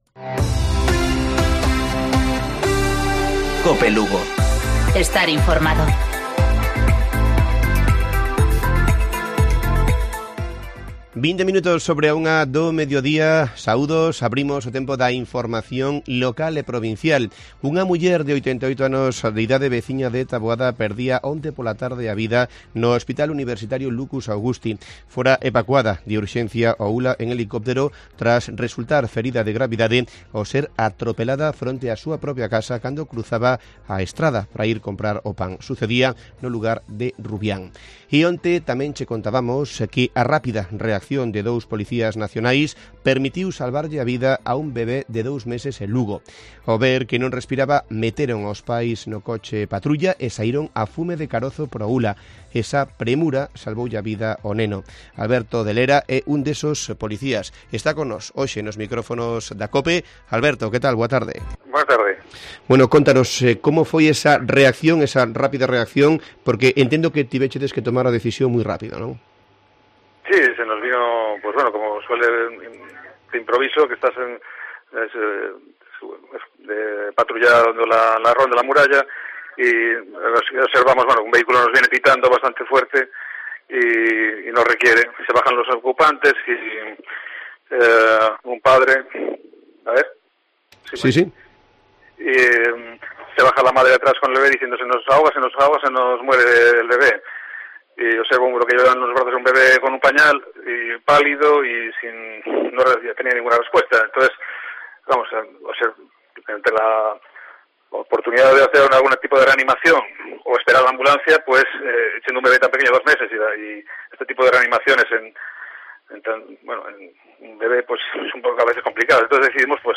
Informativo Provincial Cope Lugo. 23 de julio. 13:20-13:30 horas